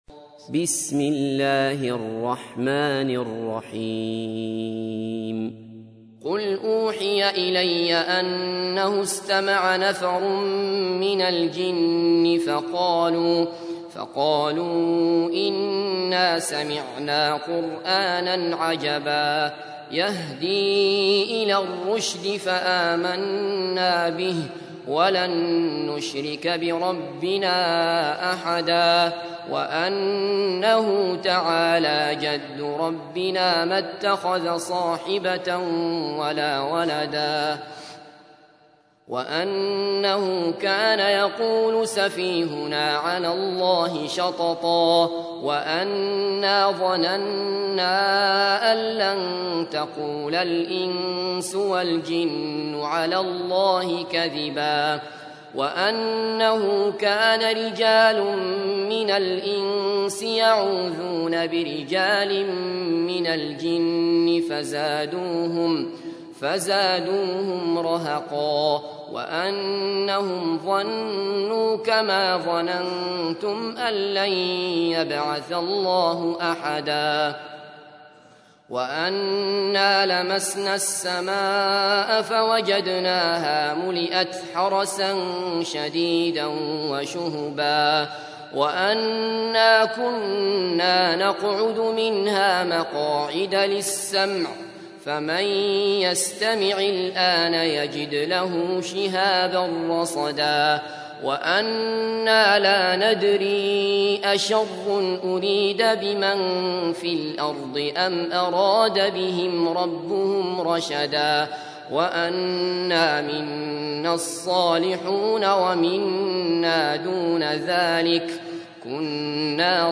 تحميل : 72. سورة الجن / القارئ عبد الله بصفر / القرآن الكريم / موقع يا حسين